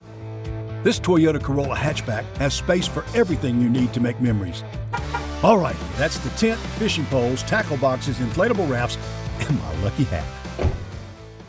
Commercial - Toyota Corolla Hatchback - Automotive
English - USA and Canada
Middle Aged
Home Studio (AT2020, ProSonus AudioBox, MacBook Pro, Audacity)